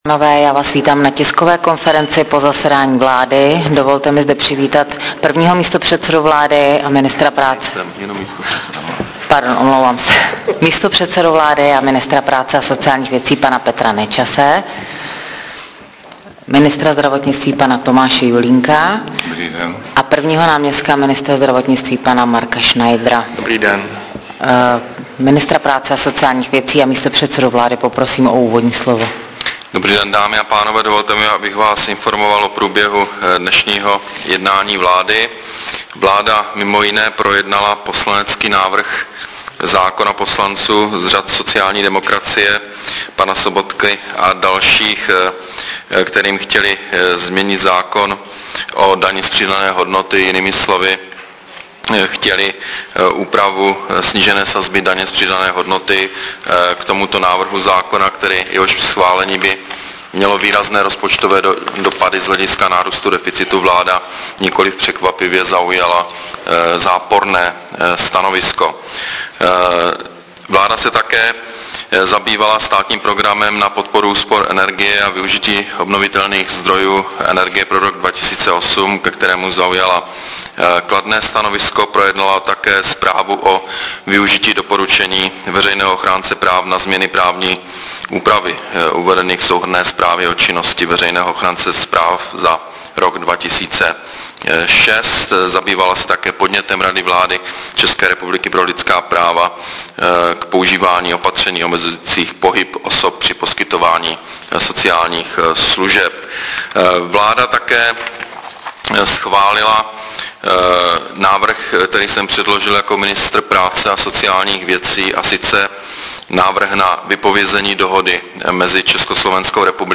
Tisková konference po jednání vlády 21.11.2007